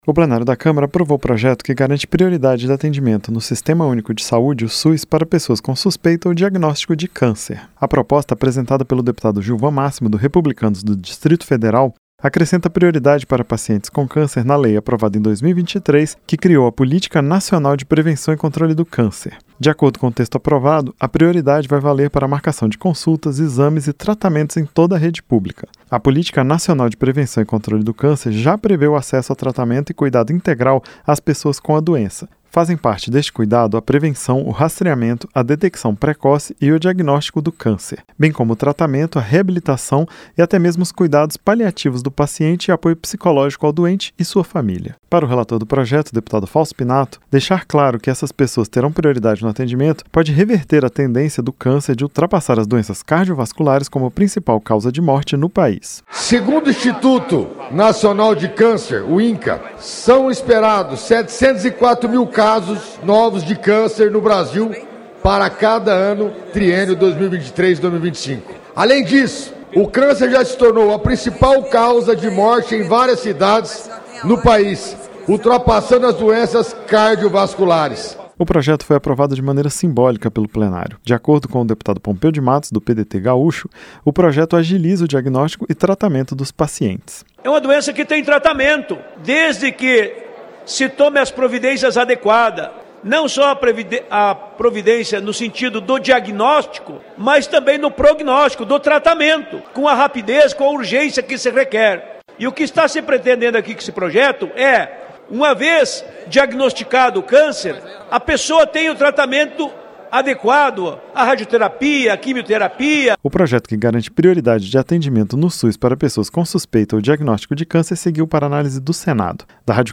Câmara aprova prioridade no SUS para o atendimento a pacientes com câncer - Radioagência